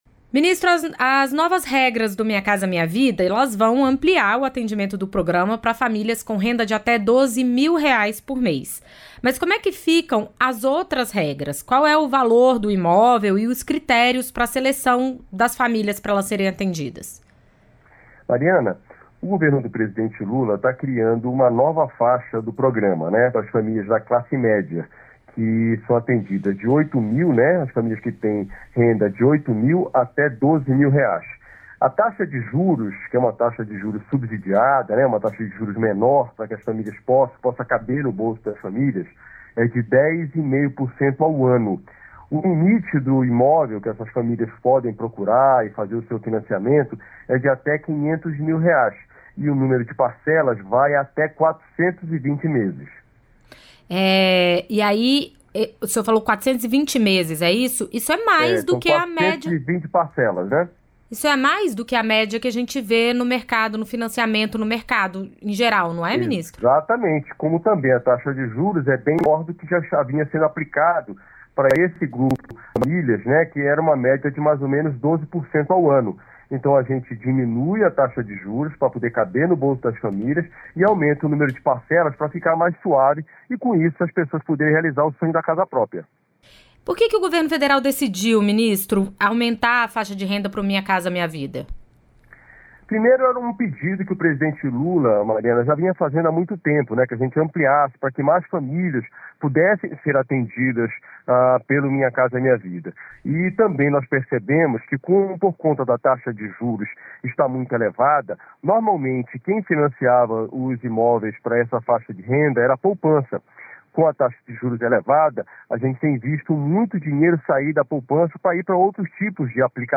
Em entrevista à Voz do Brasil, o ministro fala sobre as ações realizadas no Sudeste para reduzir os danos causados pelas chuvas do fim de semana.